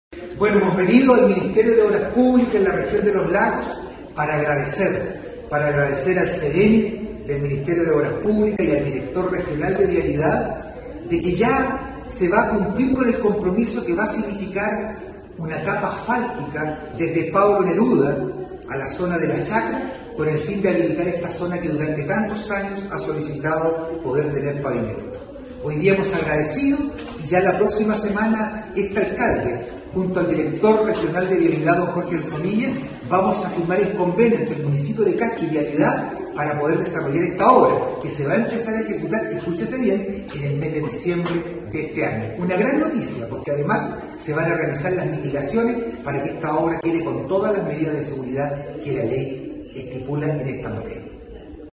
El alcalde Juan Eduardo Vera indicó que se trata de una buena noticia y anticipó que la próxima semana se firmaría el convenio respectivo con el director regional de Vialidad para comenzar en diciembre con los trabajos propiamente tal.
ALCALDE-VERA-EN-MOP.mp3